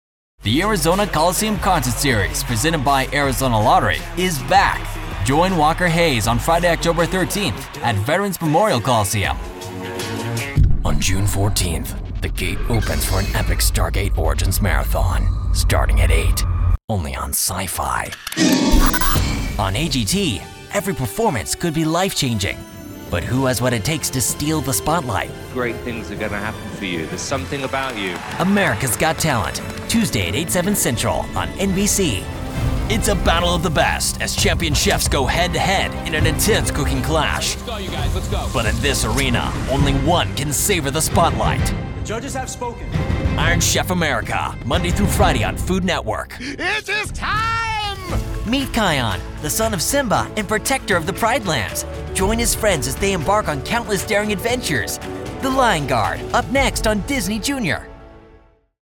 I can deliver a sharp and professional read for corporate and branded content or shift into a warm, conversational tone that feels natural and engaging.
Working from a professional home studio allows for fast turnaround times without sacrificing quality.
Male Voice Samples
I specialize in young adult voices, but I always look forward to a character voice or a challenge!